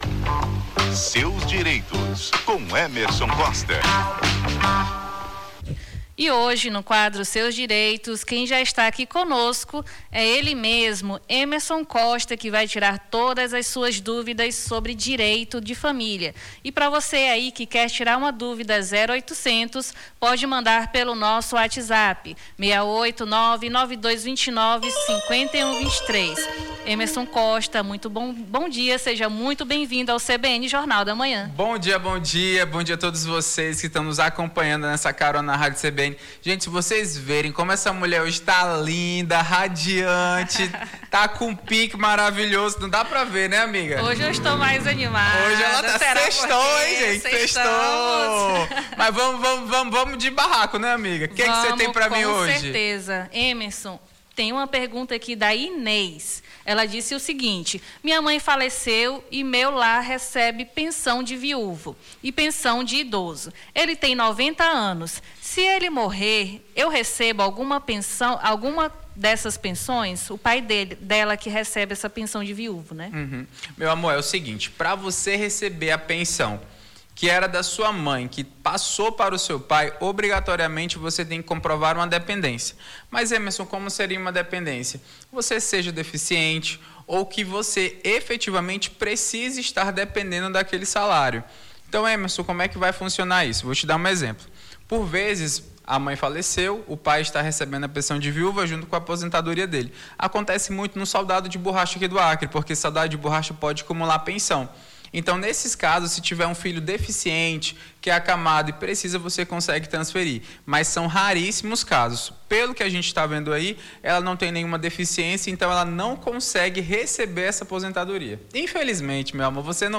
Seus Direitos: advogado esclarece dúvidas sobre direito de família